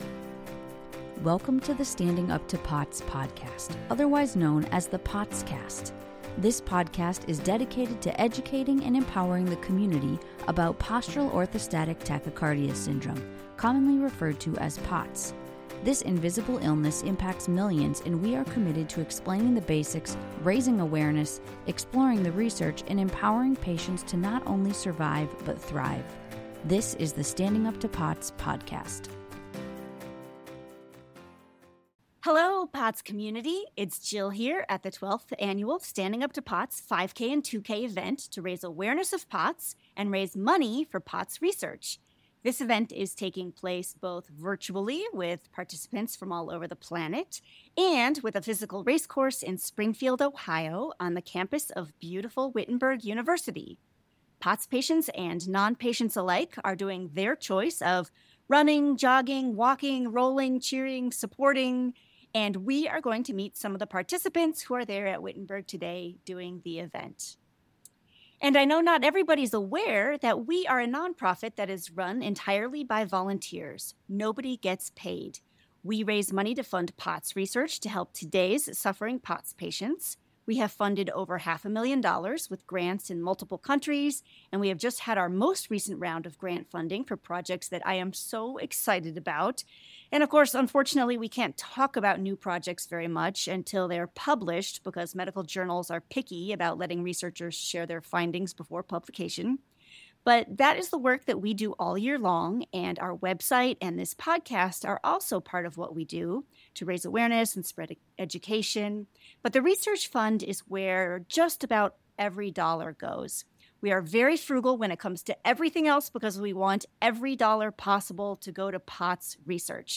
Live from the 12th annual Run for Research - interviews with participants
Our 12th annual 5k/2k took place virtually and at Wittenberg University in Springfield Ohio and raises funds for research to help today's struggling POTS patients. We interviewed several participants while they were waiting for the event to begin and got a chance to find out what brings them to the event, why they want to raise awareness of POTS and more.